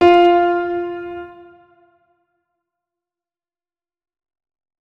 3098b9f051 Divergent / mods / Hideout Furniture / gamedata / sounds / interface / keyboard / piano / notes-41.ogg 60 KiB (Stored with Git LFS) Raw History